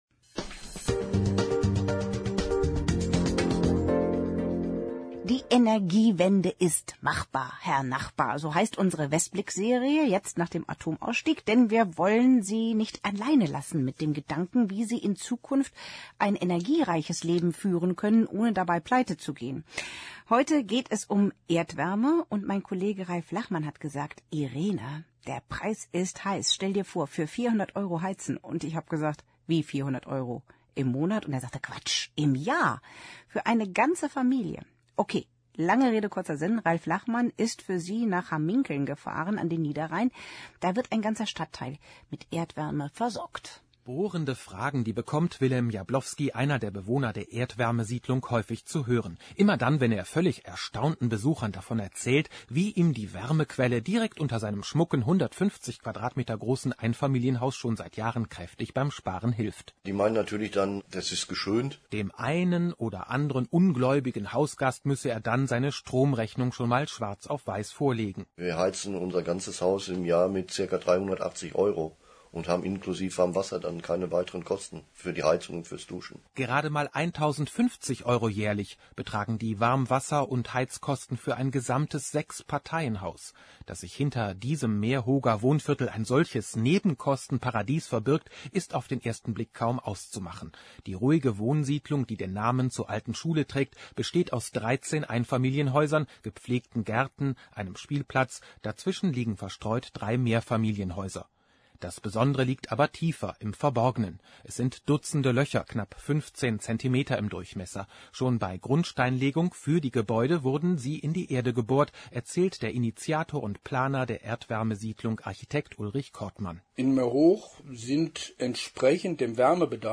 Gast im Studio